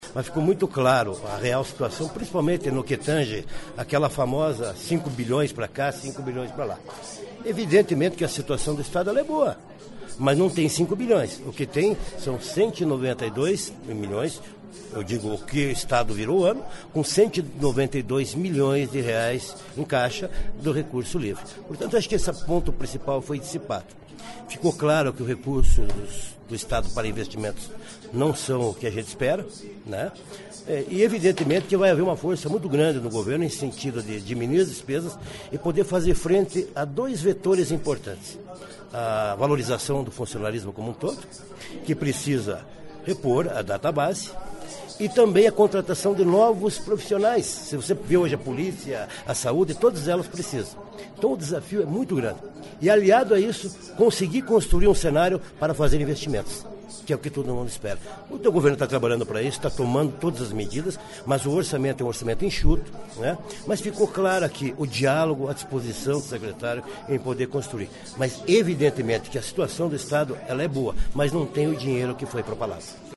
Ouça a entrevista com o líder do Governo Ratinho Júnior na Alep, o deputado Hussein Bakri.